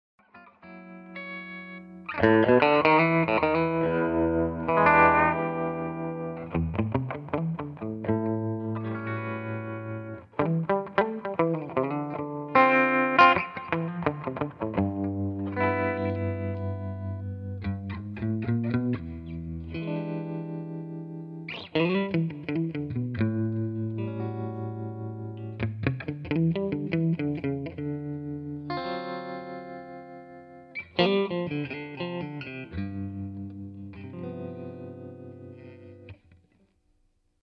REC: Rode NT1 and a Sure sm57 into Cubase | No effects added.